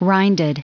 Prononciation du mot rinded en anglais (fichier audio)
Prononciation du mot : rinded